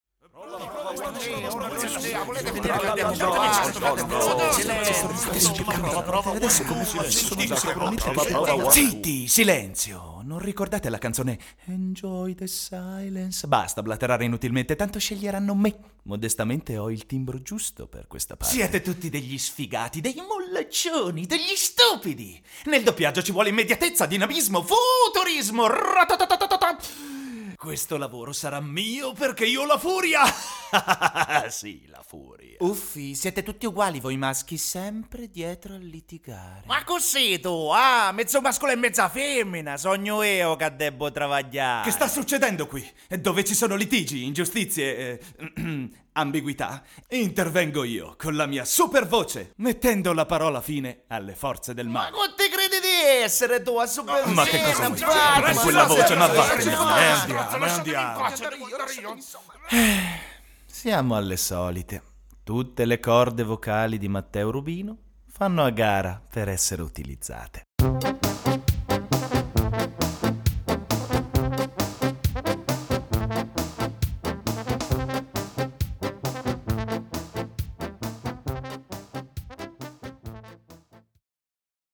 Warm, strong, clear, light, stylish...my versatile voice is avalaible for you now.
Italian voice over artist Sprecher italienisch Caratteristiche: Voce 20-40 anni TV Radio commercials, documentaries, tutorials, industrial voice over videos
Sprechprobe: Sonstiges (Muttersprache):